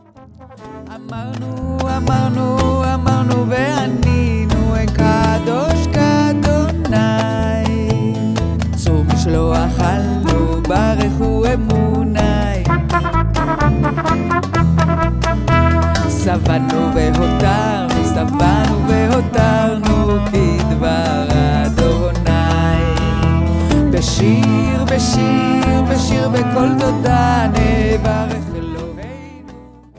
In her warm, deep voice